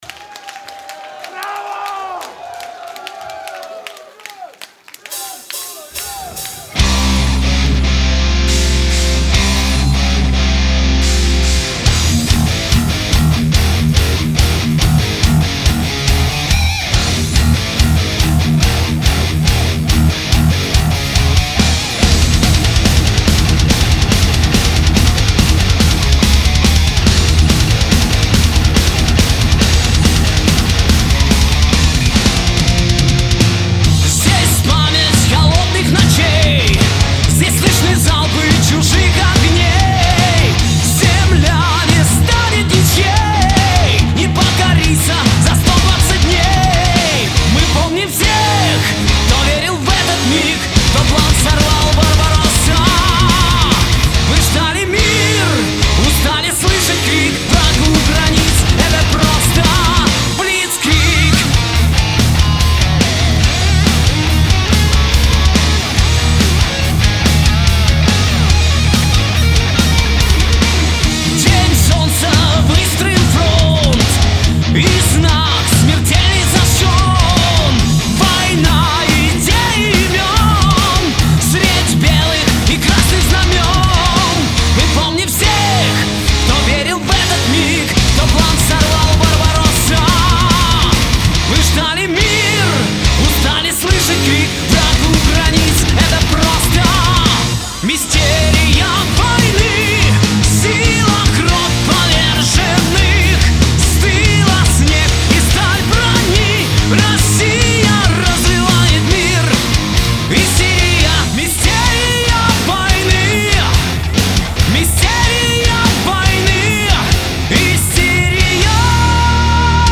с готовящегося к изданию первого концертного альбома группы